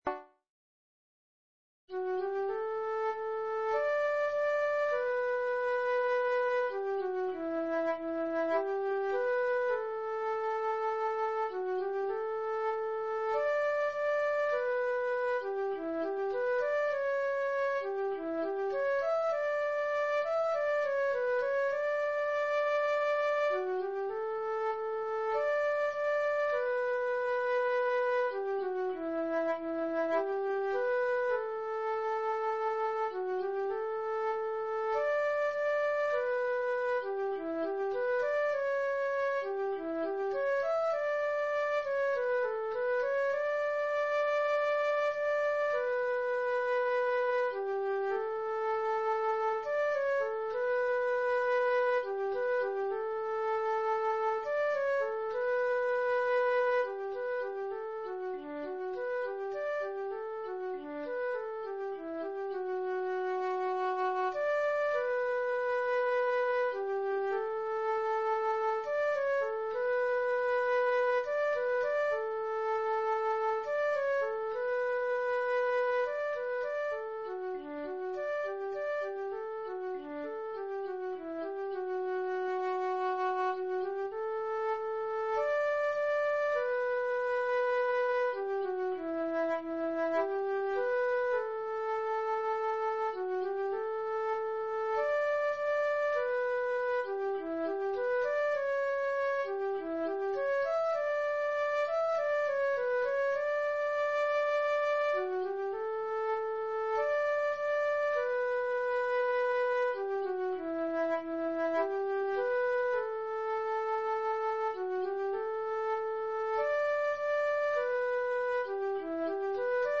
Marcha de Procesión – Pezas para Gaita Galega
Primeira voz
san-roques-dog-punteiro-1-d.mp3